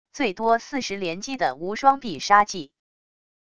最多40连击的无双必杀技wav音频